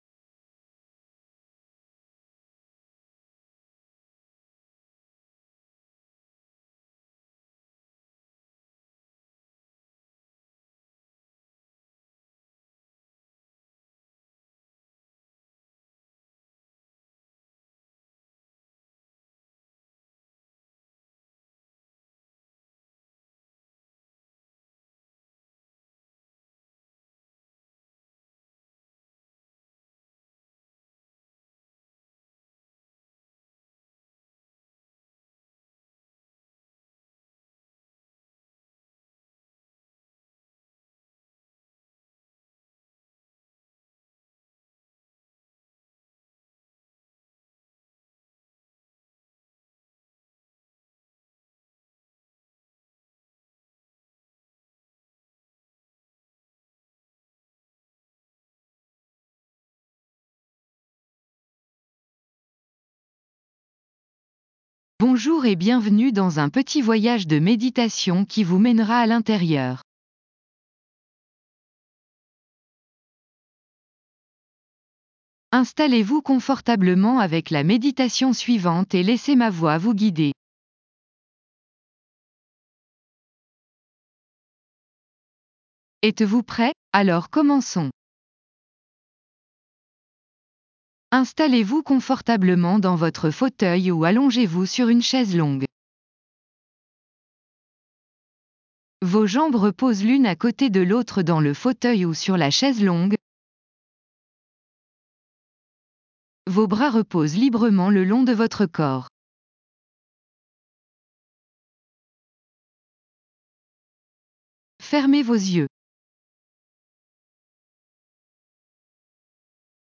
Restez concentré sur ces passages pendant que vous laissez la musique vous submerger.